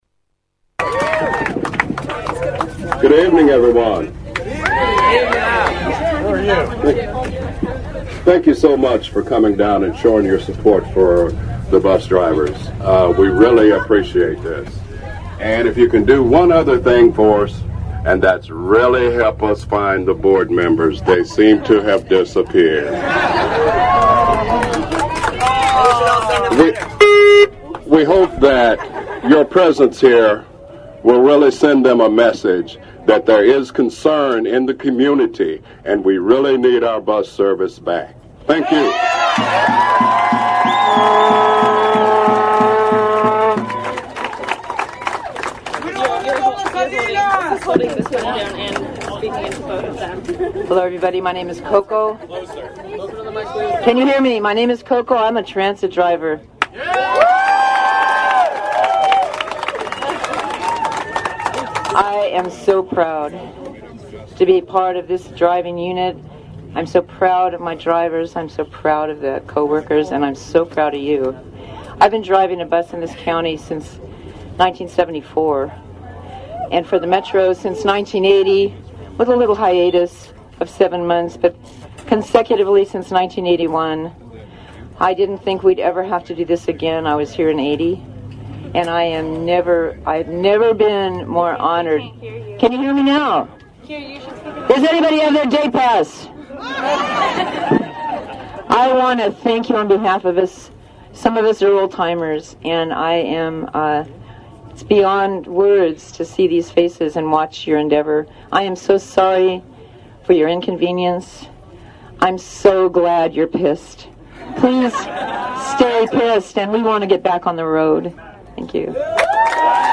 The students marched from the base of UCSC campus to the downtown metro center. These audio clips are speakers and interviews from the rally after the march at the downtown metro center.
01Two_Bus_drivers.mp3